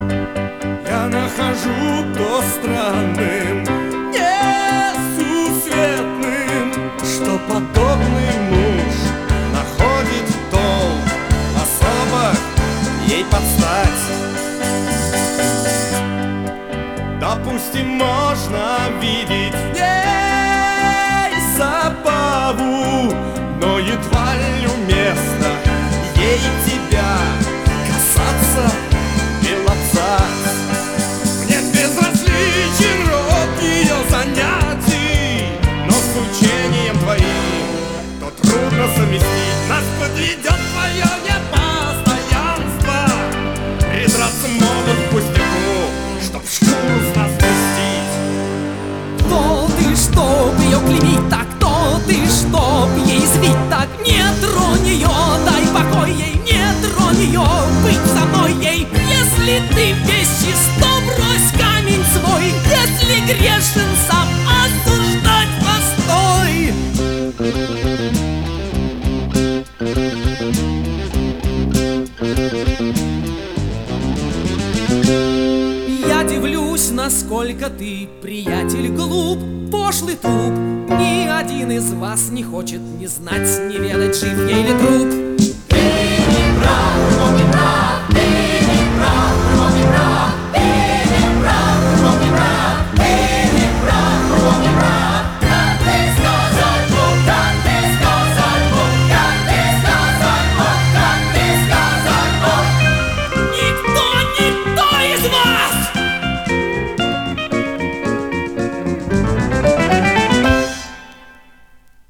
Жанр: Рок-опера
Формат: Vinil, 2 x LP, Stereo, Album
Стиль: Вокал